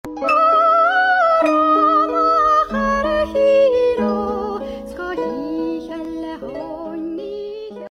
Baby Chimp Singing
Baby-Chimp-Singing.mp3